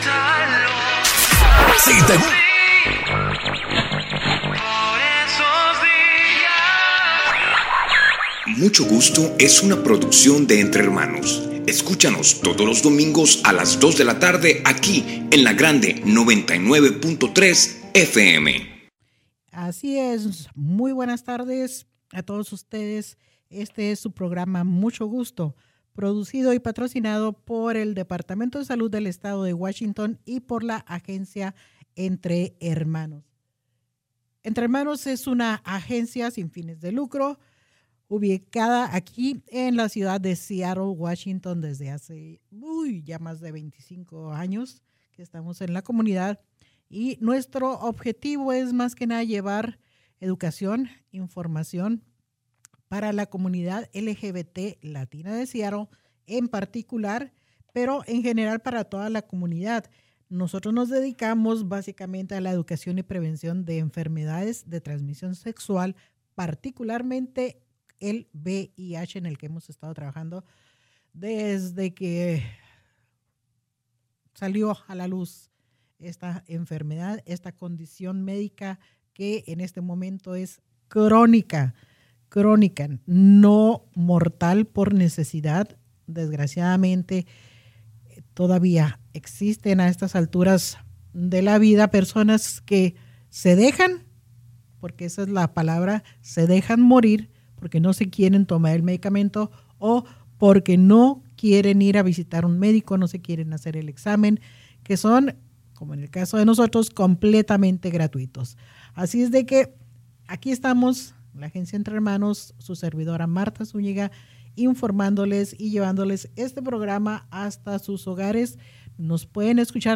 por sus conocimientos y sus experiencias las cuales nos trasladaron a través de esta radio conversación.